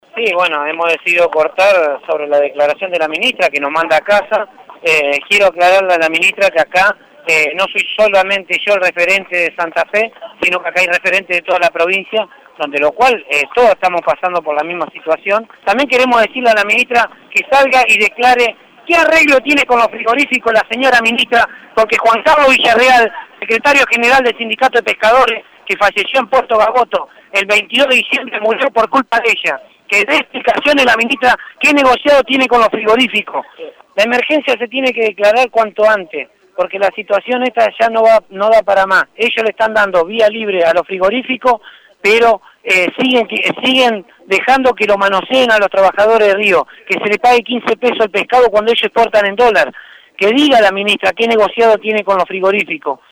en dialogo con Radio EME: